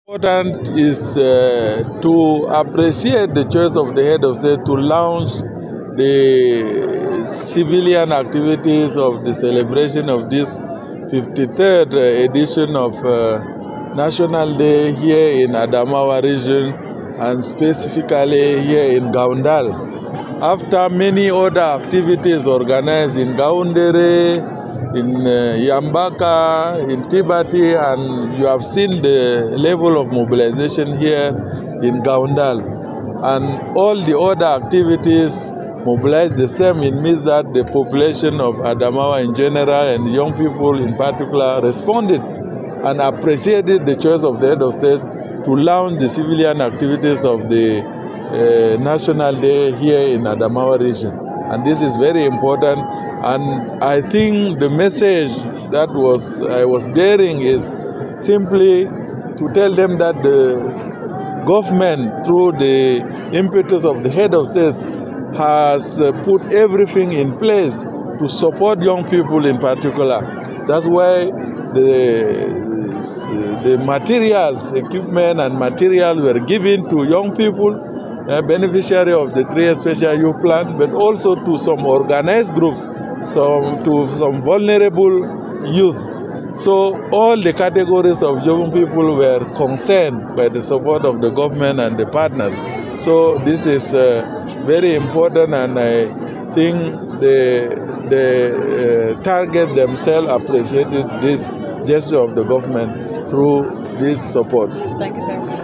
Launch speeches